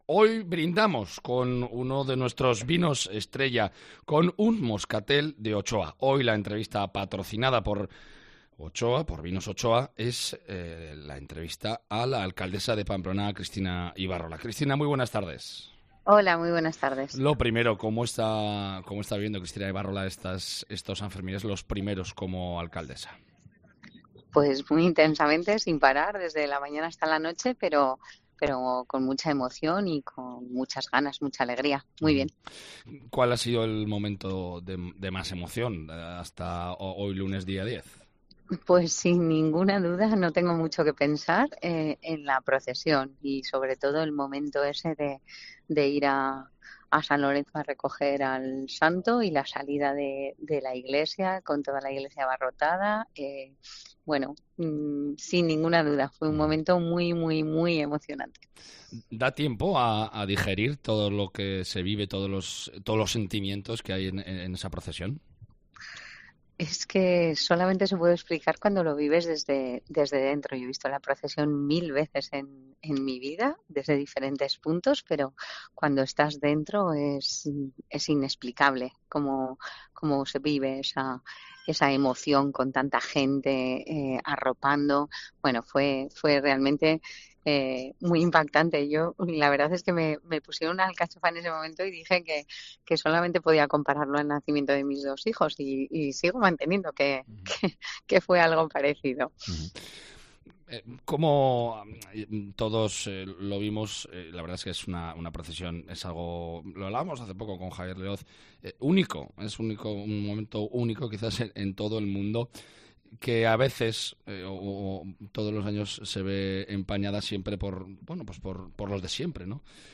Cristina Ibarrola, entrevista del día con Bodegas Ochoa
La alcaldesa de Pamplona Cristina Ibarrola explica en los micrófonos de Cope cómo está viviendo sus primeros sanfermines al frente de la corporación